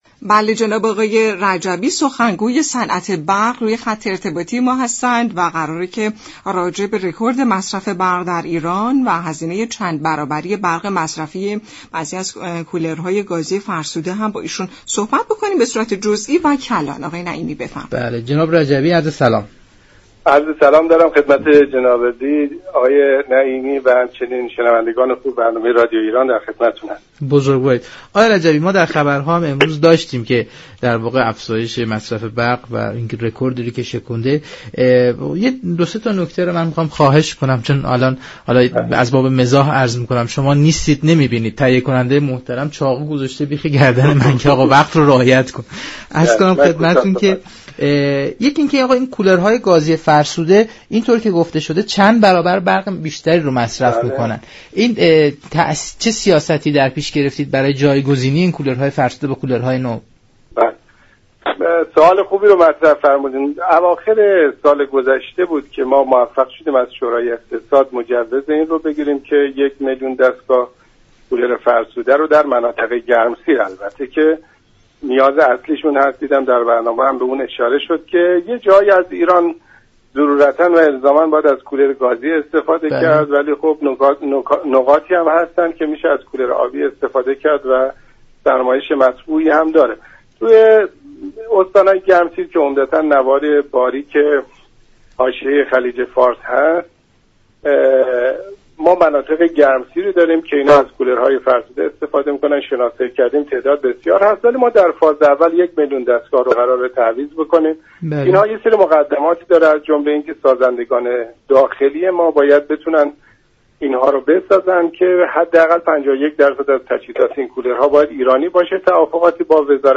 برنامه نمودار شنبه تا چهارشنبه هر هفته ساعت 10:20 از رادیو ایران پخش می شود.